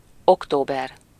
Ääntäminen
Vaihtoehtoiset kirjoitusmuodot (vanhentunut) Octobr (vanhentunut) Octobre Ääntäminen US : IPA : [ɑkˈtoʊ.bɚ] UK : IPA : /ɒkˈtəʊ.bə/ Tuntematon aksentti: IPA : /ɑkˈtoʊbəɹ/ Lyhenteet ja supistumat Oct.